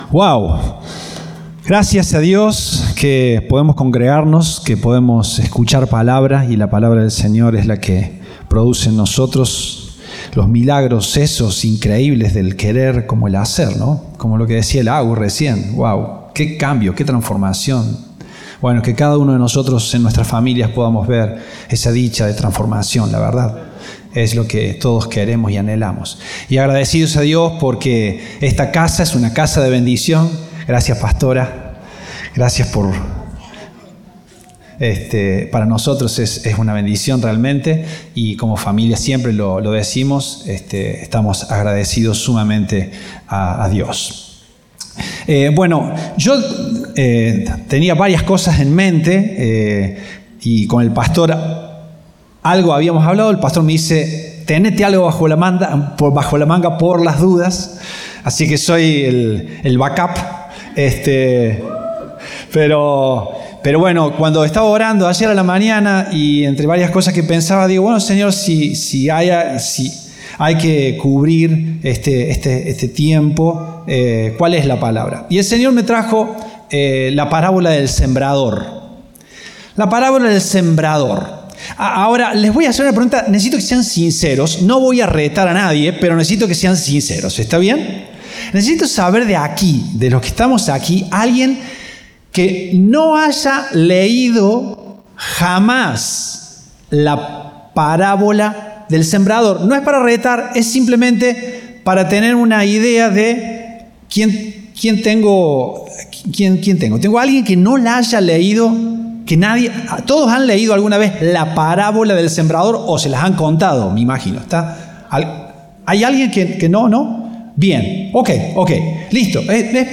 Compartimos el mensaje del Domingo 14 de Setiembre de 2025